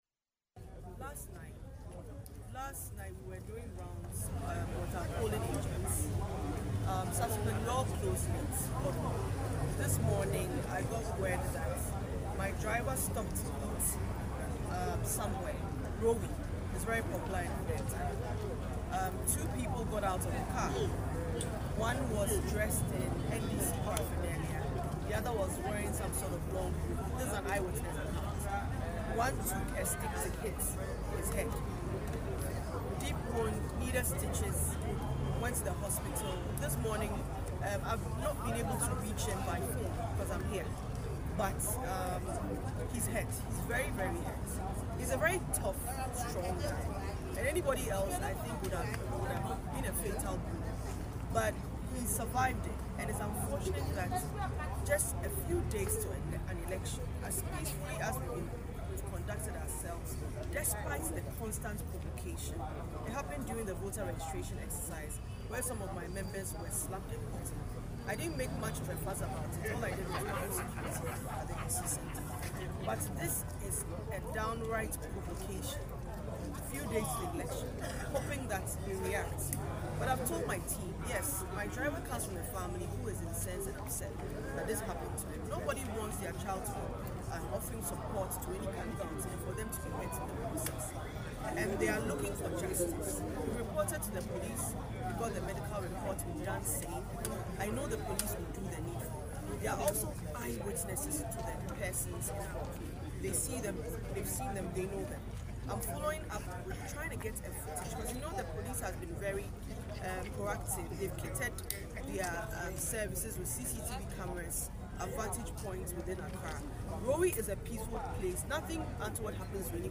In an interview on Accra-based ChannelOne TV